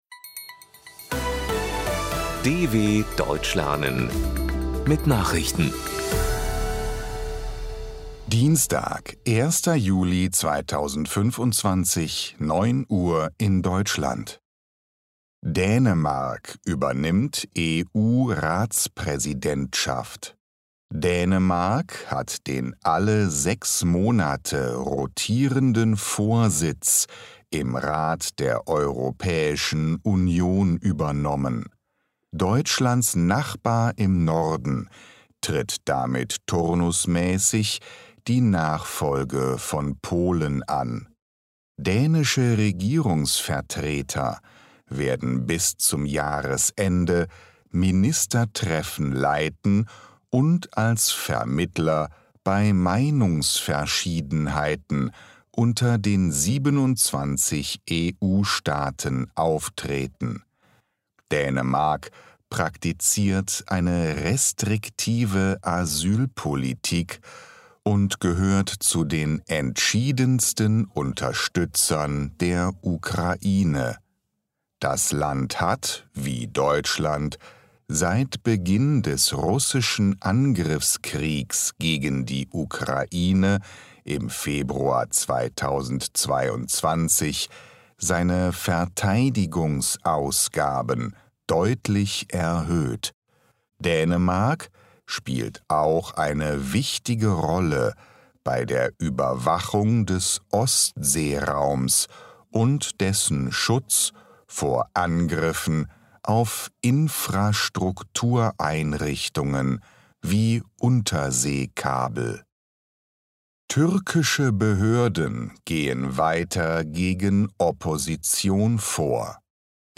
Langsam Gesprochene Nachrichten | Audios | DW Deutsch lernen
01.07.2025 – Langsam Gesprochene Nachrichten